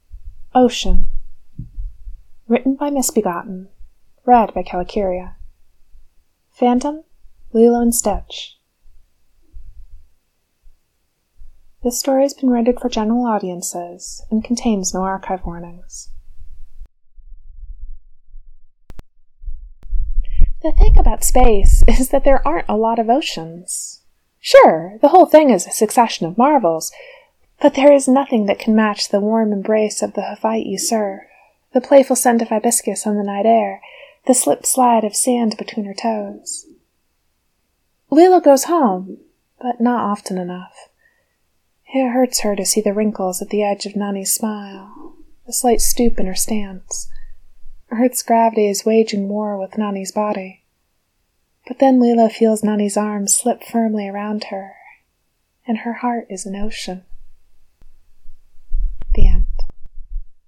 Lilo & Stitch, Moana podfics for the Chromatic Characters Podfic Anthology